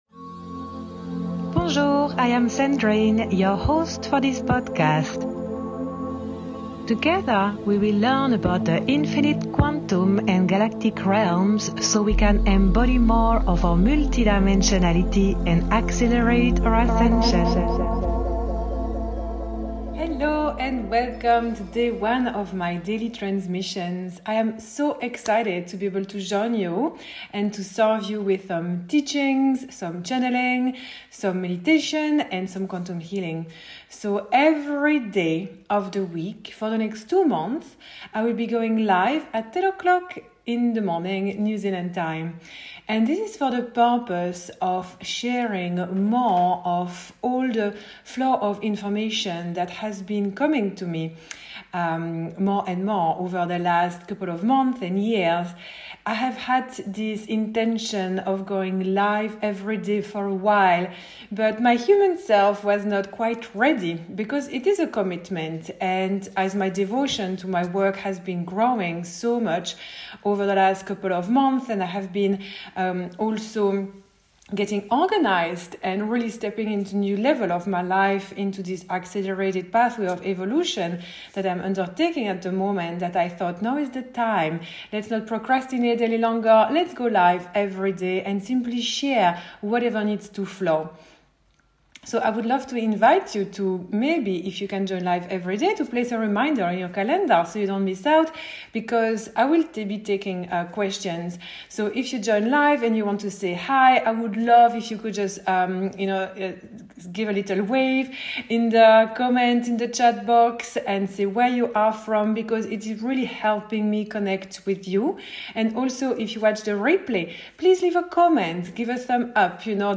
This episode is a recording from a daily Livestream on my YouTube channel!